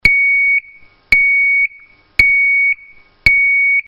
ZUMBADOR SONIDO CONTINUO Y/O INTERMITENTE
Zumbador de fijación mural
90dB